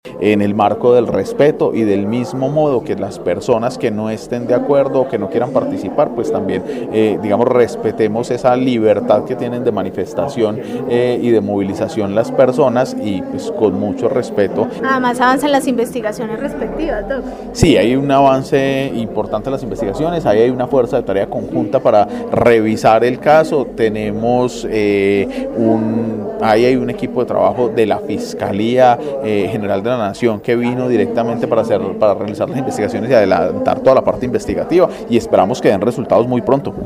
Andrés Buitrago, secretario de Gobierno de Armenia